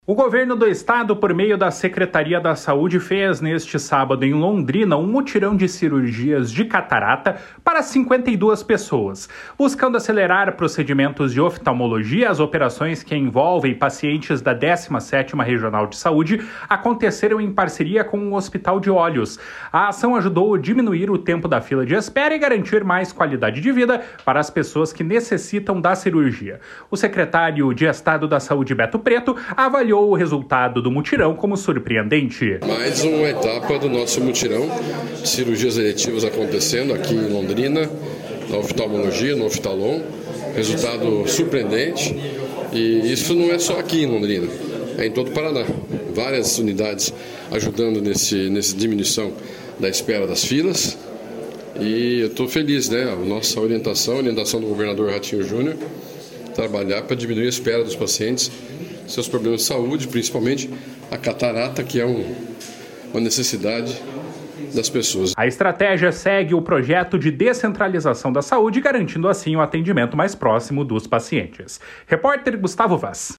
O secretário de Estado da Saúde, Beto Preto, avaliou o resultado do mutirão como surpreendente. // SONORA BETO PRETO //
Repórter